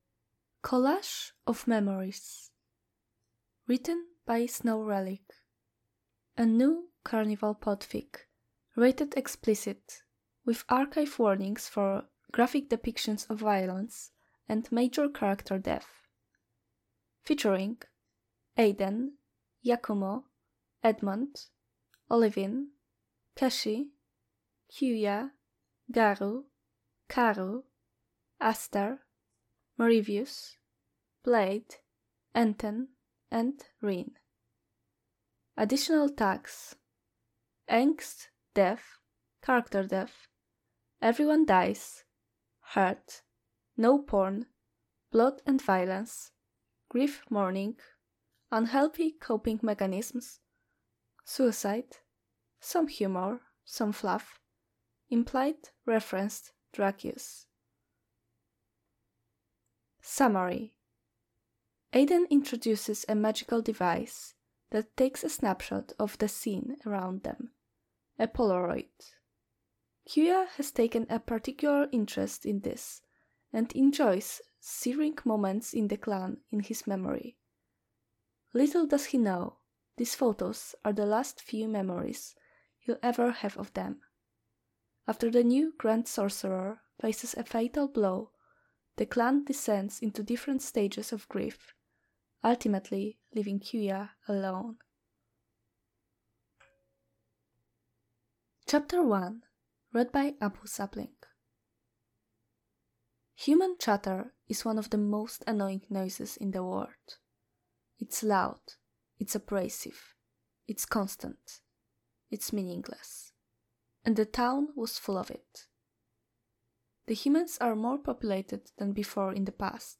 version with freetalk & outtakes: download mp3: here (r-click or press, and 'save link') [22 MB, 00:29:48]